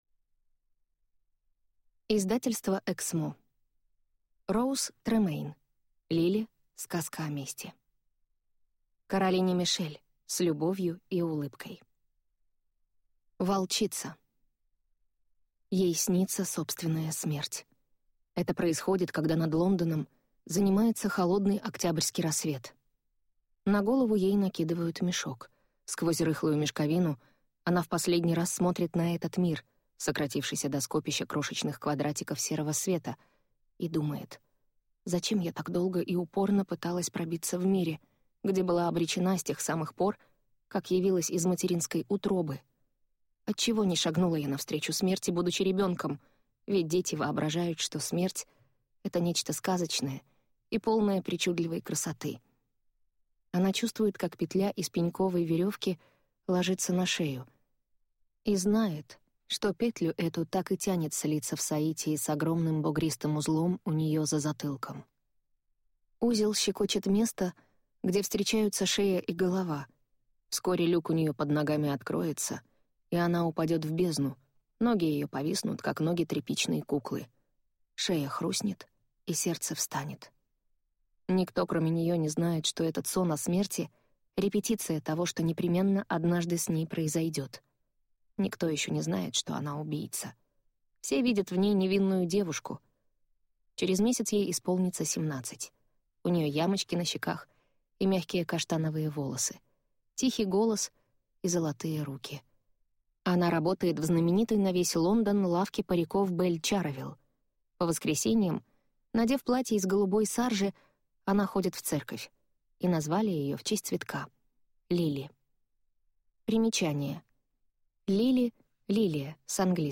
Аудиокнига Лили. Сказка о мести | Библиотека аудиокниг